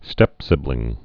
(stĕpsĭblĭng)